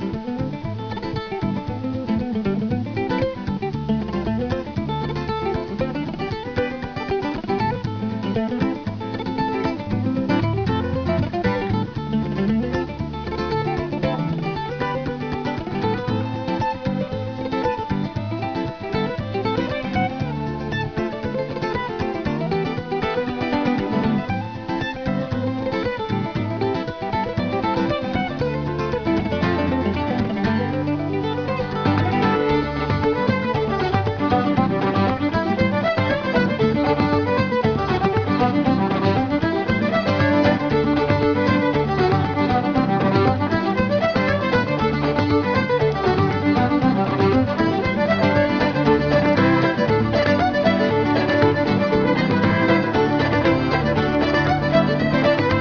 Contemporary/traditional